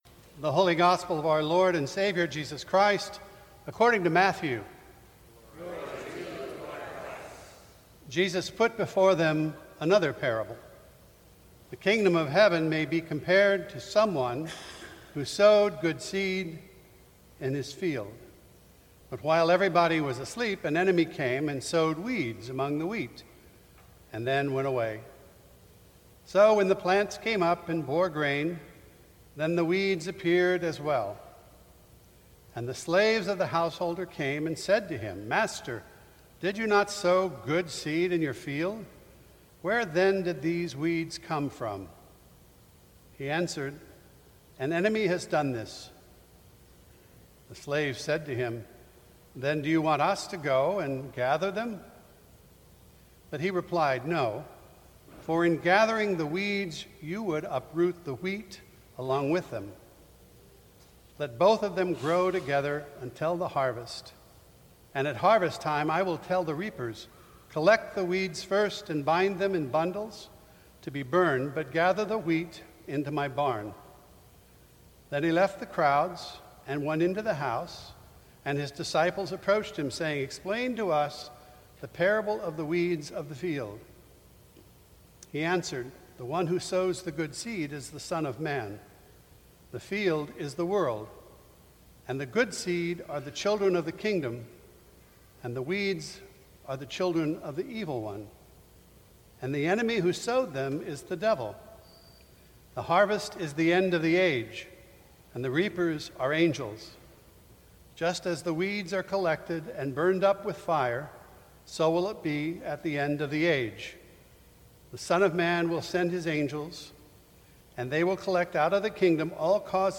10 AM Service on the Seventh Sunday After Pentecost.
Sermons from St. Cross Episcopal Church Weeds and Wheat Jul 23 2017 | 00:13:22 1x 00:00 / 00:13:22 Subscribe Share Apple Podcasts Spotify Overcast RSS Feed Share Link Embed